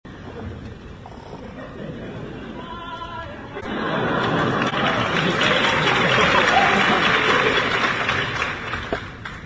２００４年１０月２２日　渋谷公会堂　２階
この素晴らしい曲のあとに、あちこちからあがる「リッチー！」の声援に甲高いまぬけ声で